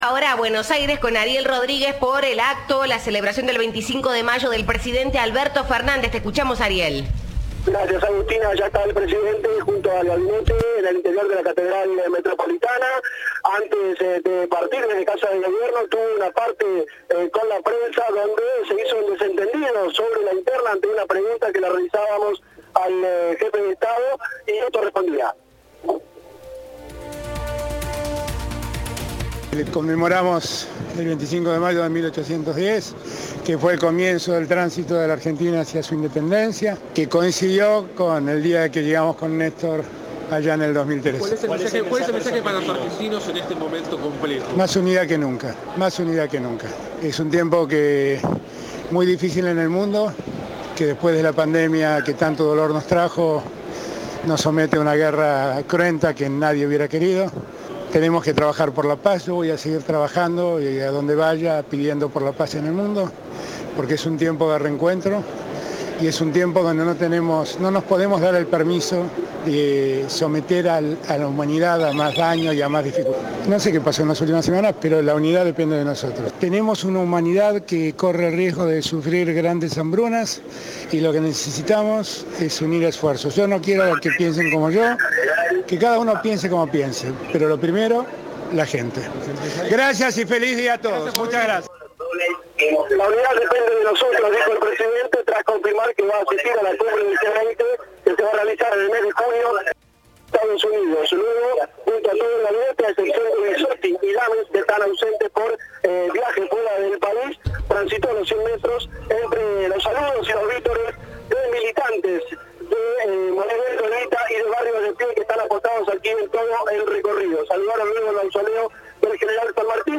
Al salir de la Casa de Gobierno para participar del Tedeum, Alberto Fernández dialogó con los periodistas acreditados y dejó un mensaje para la sociedad en la fecha patria.